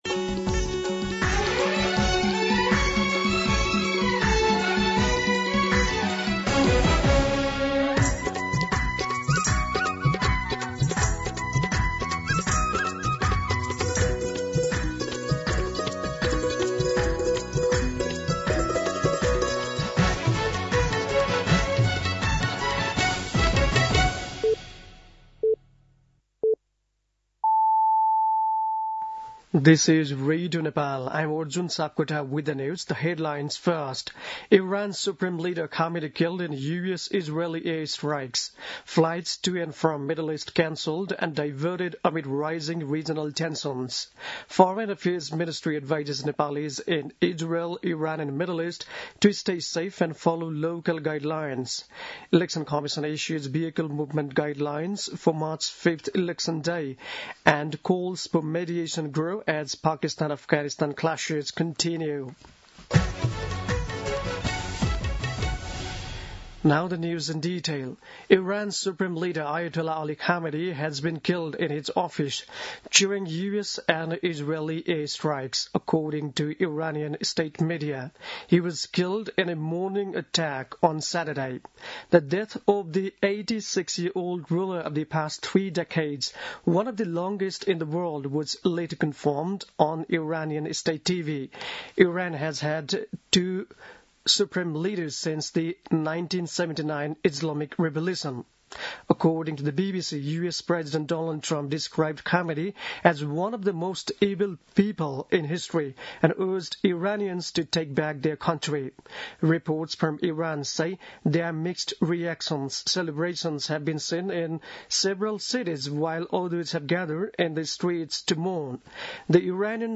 दिउँसो २ बजेको अङ्ग्रेजी समाचार : १७ फागुन , २०८२
2pm-English-News-17.mp3